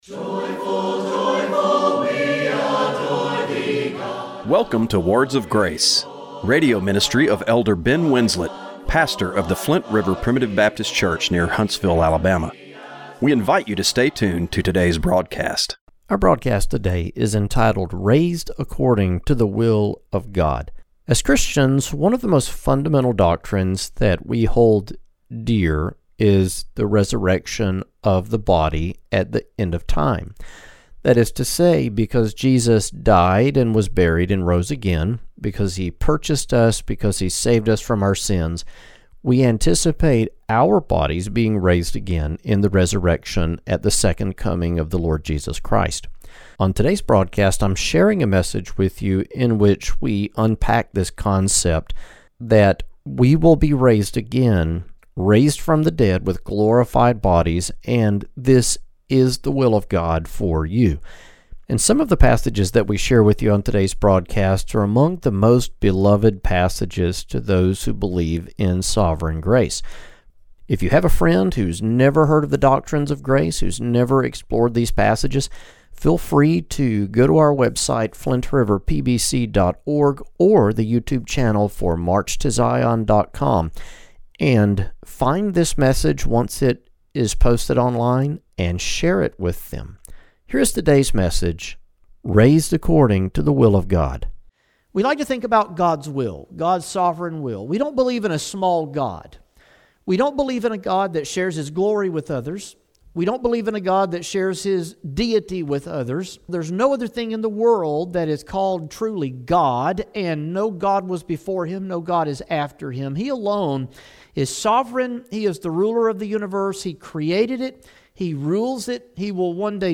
Radio broadcast for April 26, 2026.